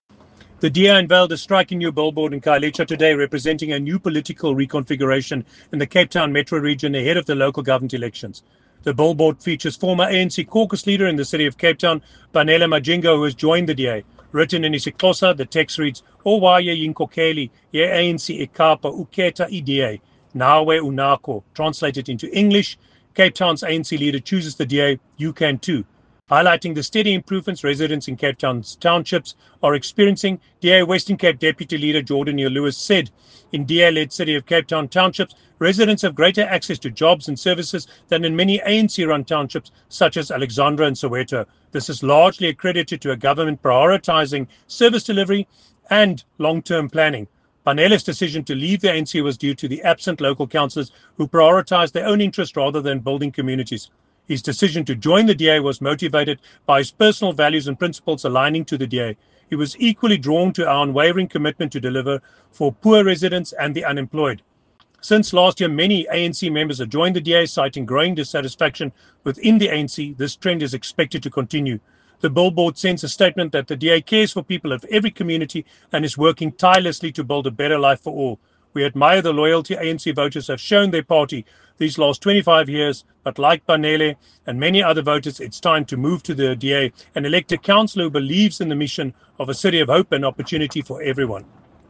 soundbite by JP Smith